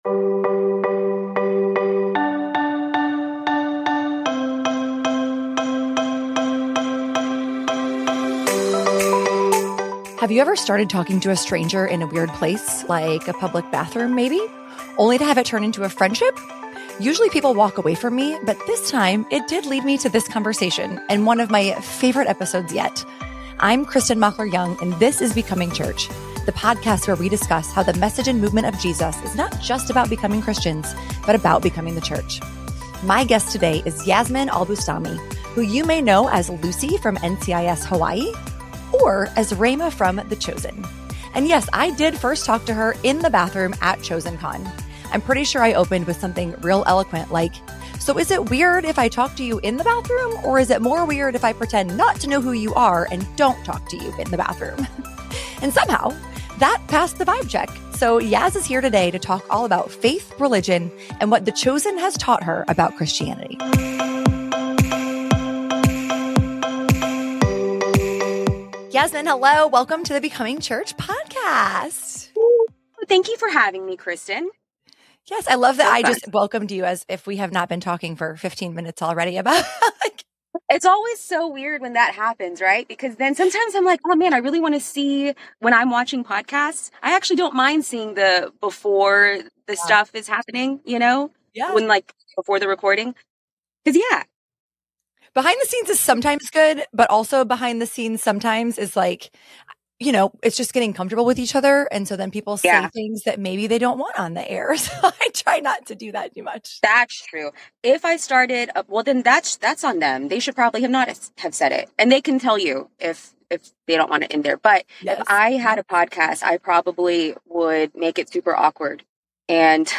Yasmine Al-Bustami, who plays the beloved Rhema on the TV show The Chosen, shares how she grew up in a multi-faith household and how it formed her beliefs. She vulnerably explains how working on The Chosen has introduced her to even more complexities in Christianity.